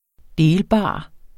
Udtale [ ˈdeːlˌbɑˀ ]